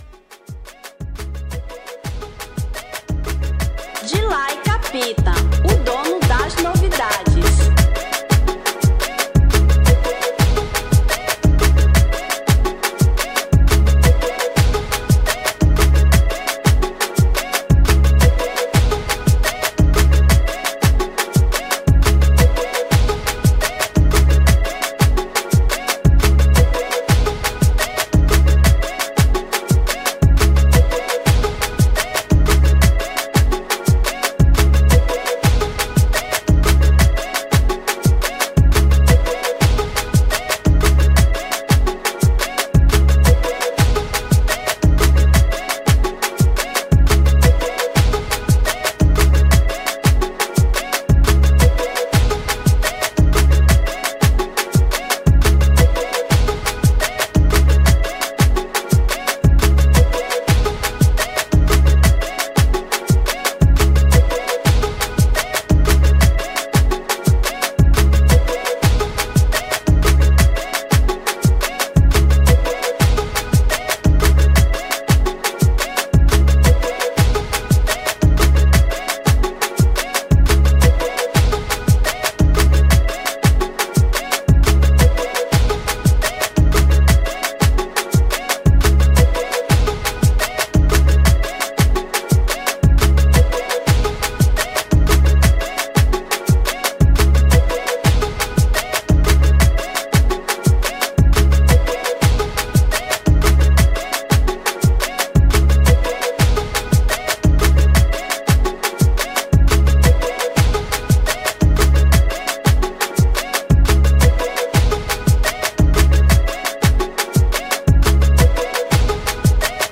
Instrumental 2018